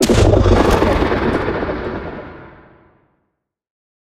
Minecraft Version Minecraft Version 1.21.4 Latest Release | Latest Snapshot 1.21.4 / assets / minecraft / sounds / mob / warden / sonic_boom1.ogg Compare With Compare With Latest Release | Latest Snapshot
sonic_boom1.ogg